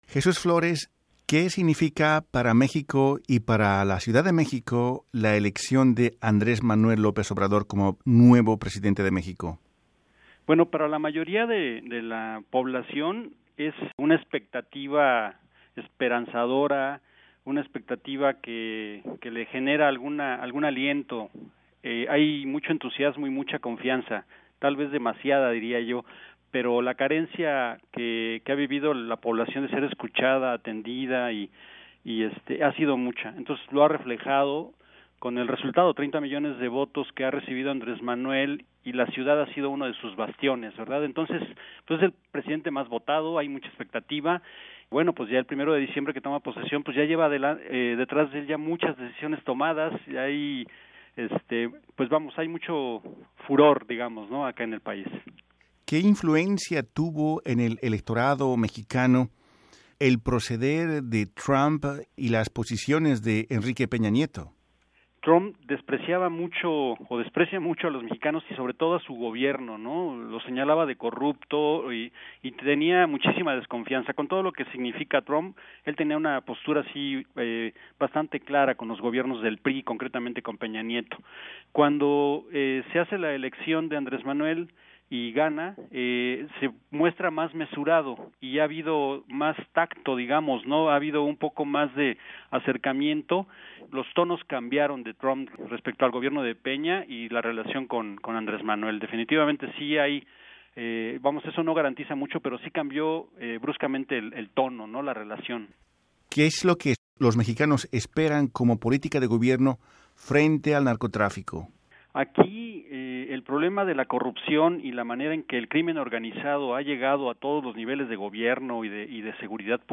En conversación con Radio Canadá Internacional